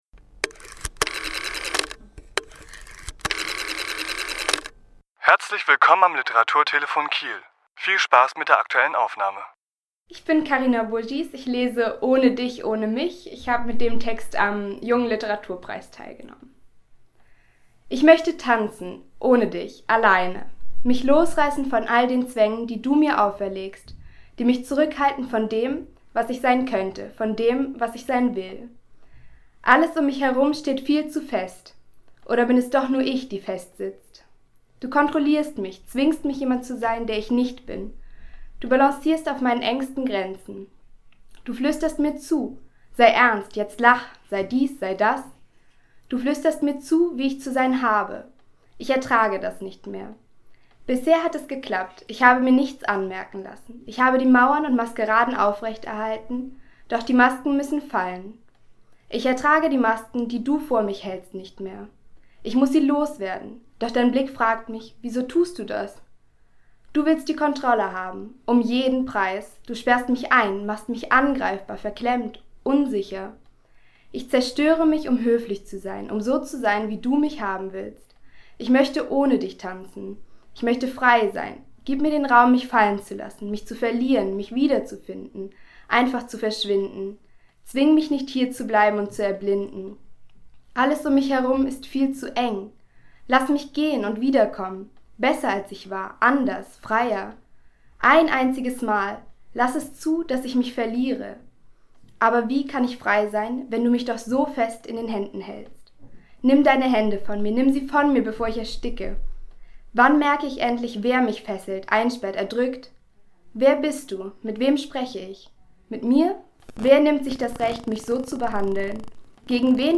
Autor*innen lesen aus ihren Werken
Die Aufnahme entstand im Rahmen der Lesung zur Preisverleihung im Literaturhaus S.-H. am 25.4.2017.